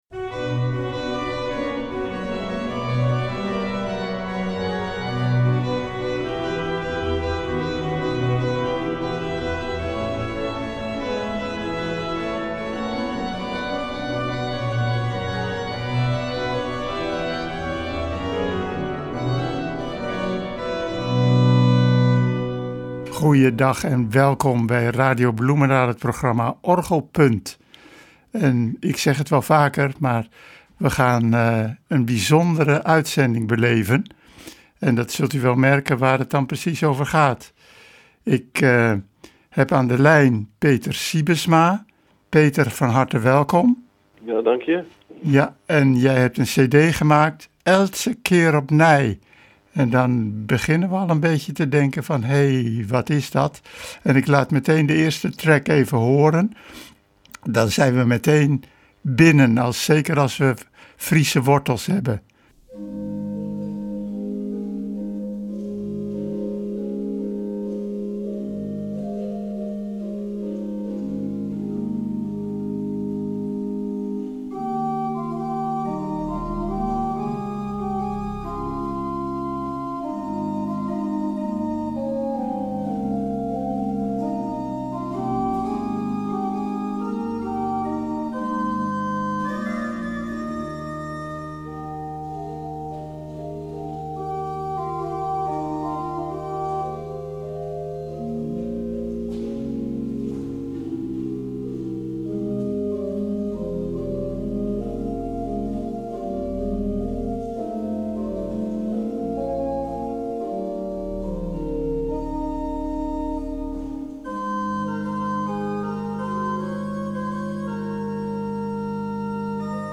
orgelimprovisator